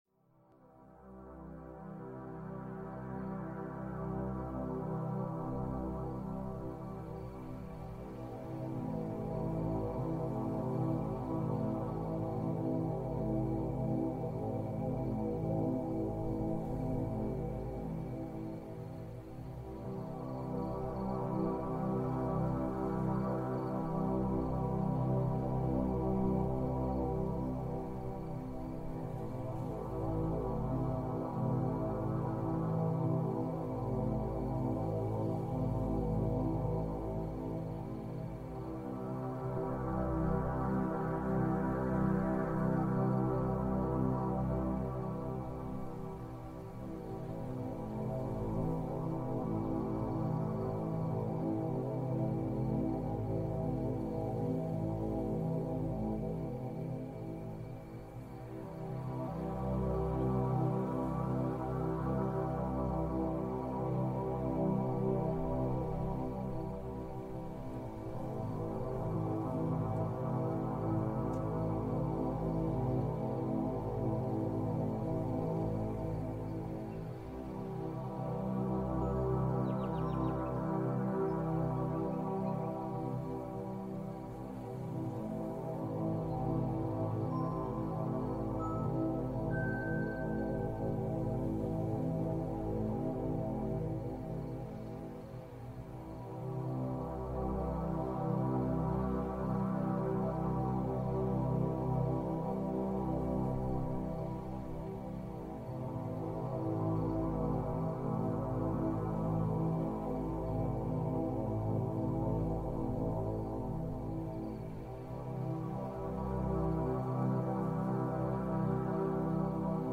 Звуки океана для глубокого отдыха Раздел: Дельта-волны
Этот часовой саундскейп создан для глубокого исцеляющего отдыха. Мощные дельта-волны, синхронизирующиеся с ритмами вашего мозга в состоянии deepest sleep, и гипнотический рокот океана мягко унесут вас прочь от суеты.
Сочетание низкочастотных дельта-волн (0.5-4 Гц) с циклическим природным звуком создает эффект нейроакустического погружения, способствуя переходу мозга в состояние немедленного глубокого отдыха.
Звуки океана для глубокого отдыха Раздел: Дельта-волны Размер: 55 MB Длительность: 59 минут Качество: 320 kbps Релиз: 30 ноября 2025 Слушать Скачать Дельта-волны воспроизводятся на разных частотах в каждом ухе, поэтому обязательно слушайте в наушниках.